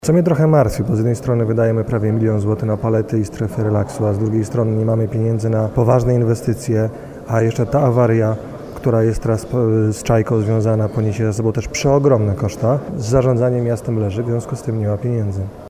– Podstawowym problemem Warszawy jest złe zarządzanie – podkreśla radny Prawa i Sprawiedliwości Patryk Górski.